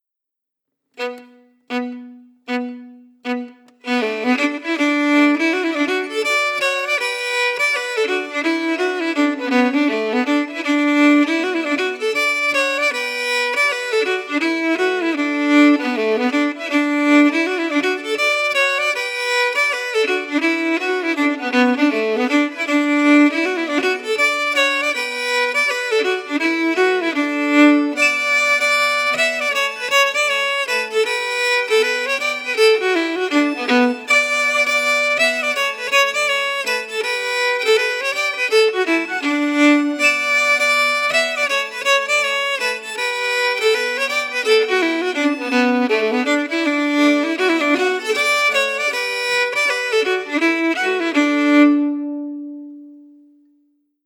Key: D
Form:Slow reel
Melody emphasis